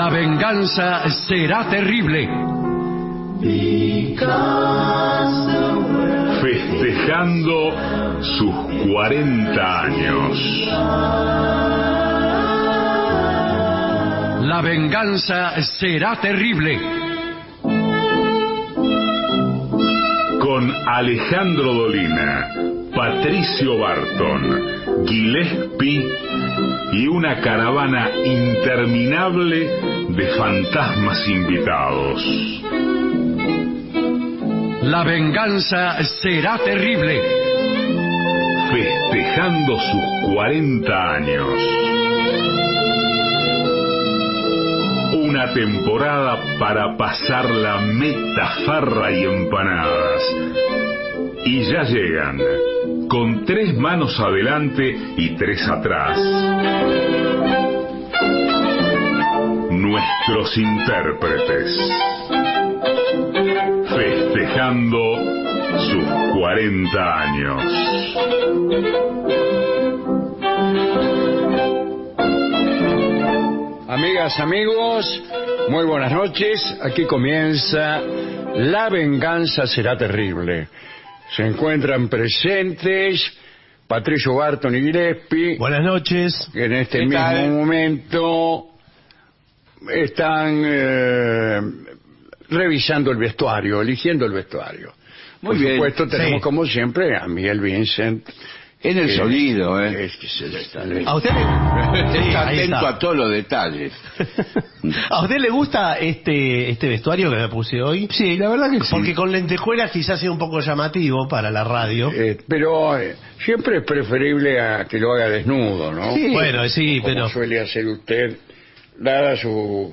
Estudios AM 750